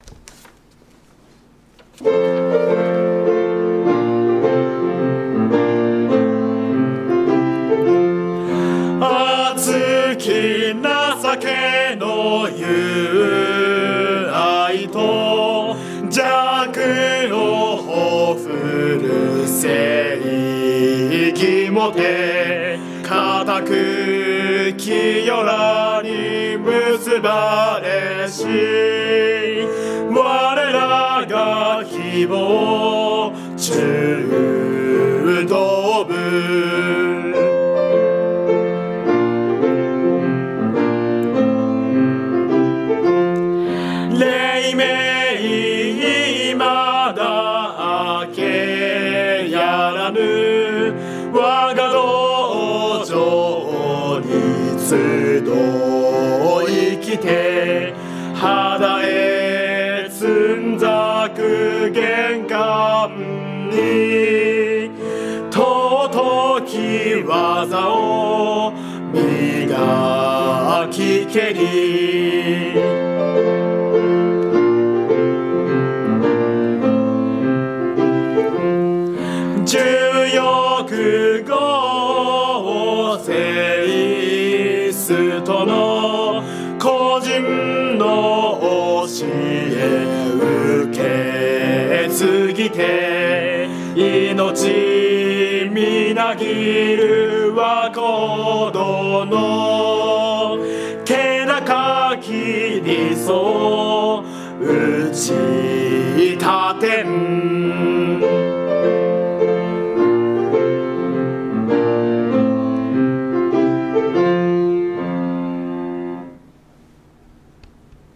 高砂高校柔道部歌（歌入り）.mp3